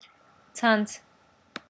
[t̻͡s̪ʰan̪t̻͡s̪ʰ] 'net' Contrasts aspirated and unaspirated forms